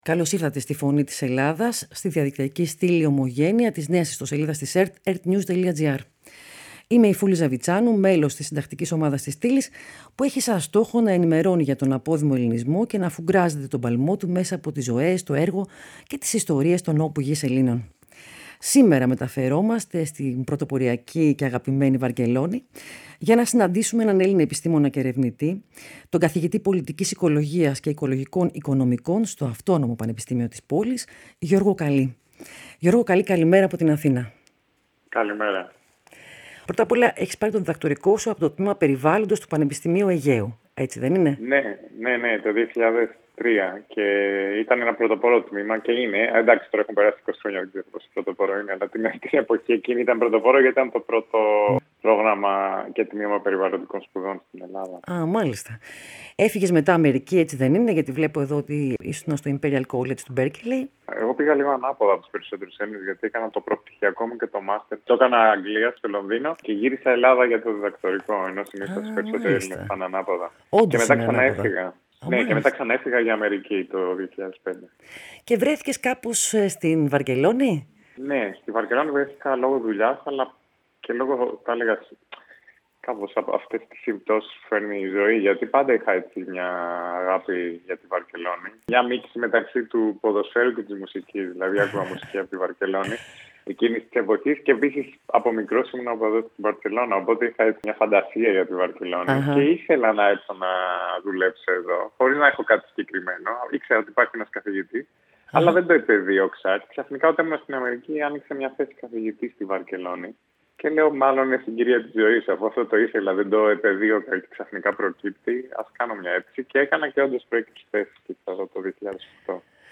στη συνέντευξή του στη Φωνή της Ελλάδας